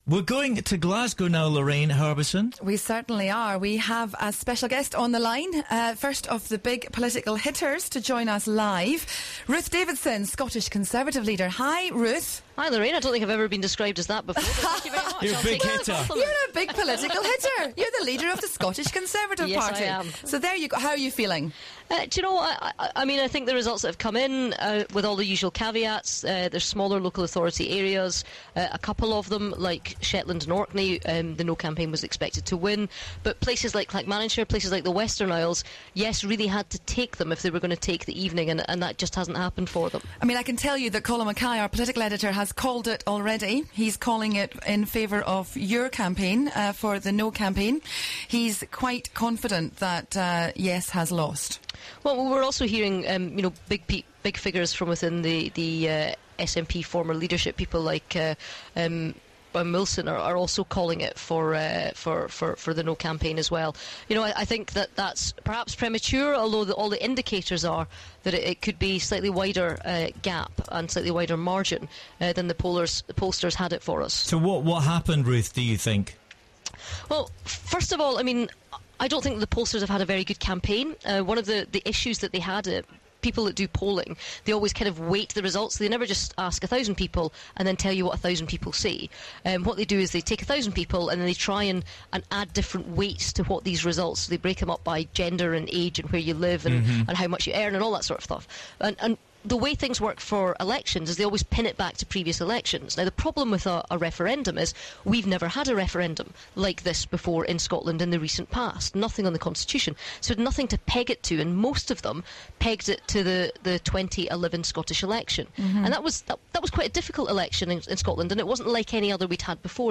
LIVE on air